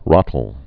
(rŏtl)